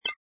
sfx_ui_react_data01.wav